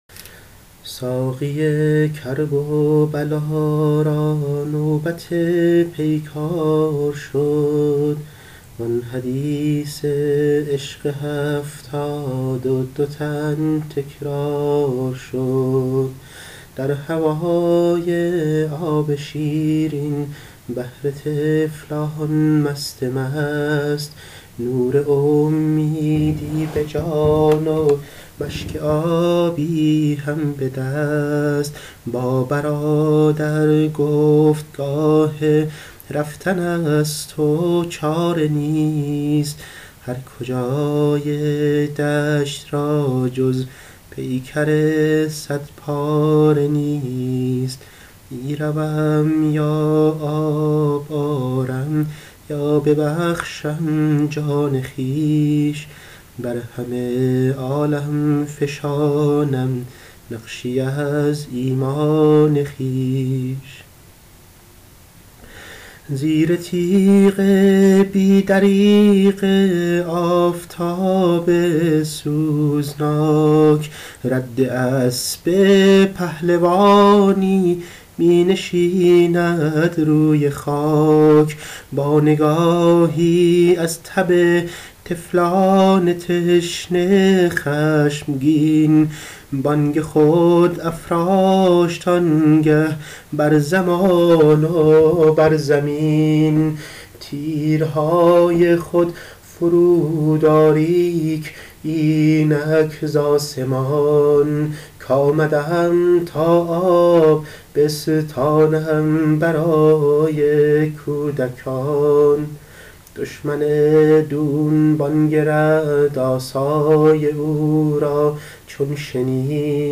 شب تاسوعا, ساقی کرب و بلا را نوبت پیکار شد, نوحه خودم